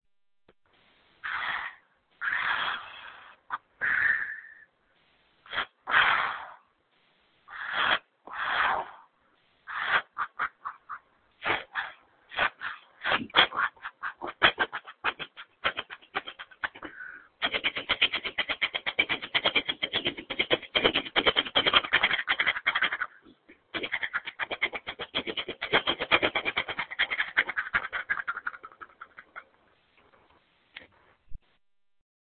Here's me impersonating a train.